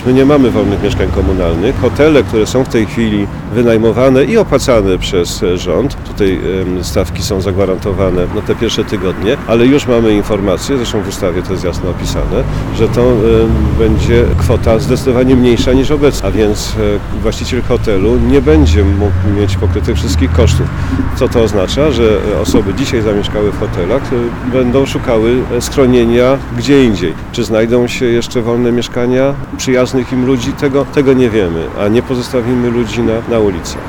– Jeśli potwierdzą się scenariusze mówiące o nawet 5 milionach uchodźców, nawet najlepiej zorganizowane miasto sobie nie poradzi – powiedział prezydent Tarnowa Roman Ciepiela na specjalnie zorganizowanej konferencji prasowej.